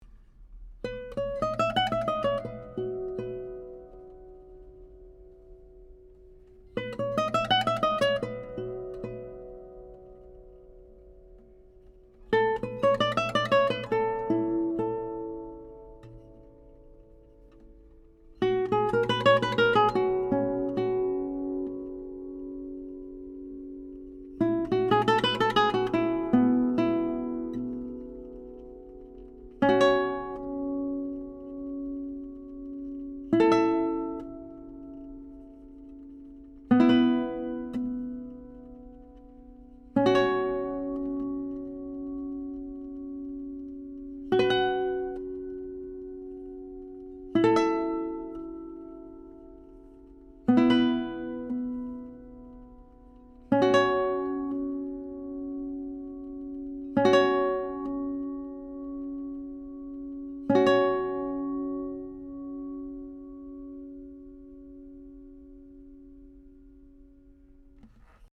MILAGRO 11-String Classical Harp Guitar
This guitar has a AAA-grade solid Cedar top (Spruce top available upon request), solid East Indian Rosewood back & Sides, ebony fretboard, and creates a beautifully-balanced sound with nice trebles, deep basses, and excellent resonance and sympathetic sustain.
Here are 12 quick, 1-take MP3s of this guitar, tracked using a Neumann TLM67 mic, into a Undertone Audio MPEQ-1 preamp using a Metric Halo ULN8 converter going into Logic. This is straight, pure signal with no additional reverb, EQ or any other effects.
1 | Resonance Test
(Showing Sympathetic Resonance)